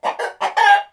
loudcluck1.wav